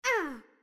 SFX_Mavka_Hit_Voice_06.wav